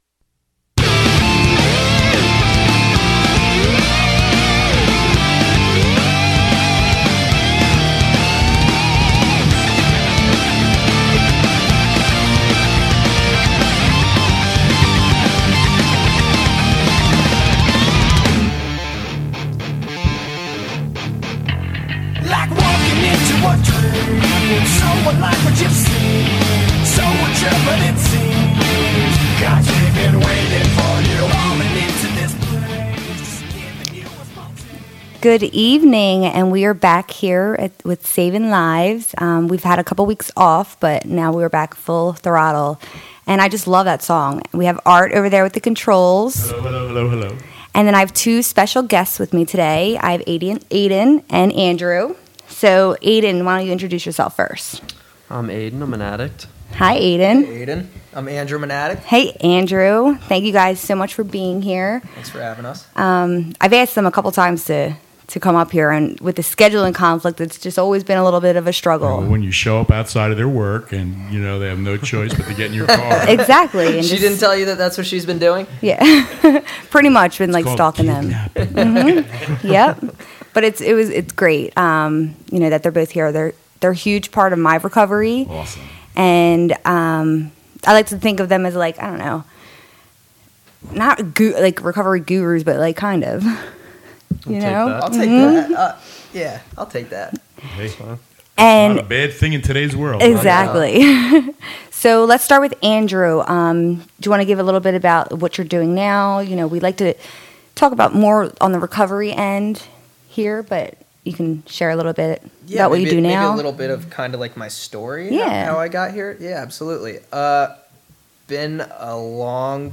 The new Saving Lives will be hosted by City of Angels as they will be visited by various guests from the recovery community and the local community offering hope, care and understanding.
In this show, COA volunteers, recovery providers, family members, recoverees and more share laughs, tears and war stories.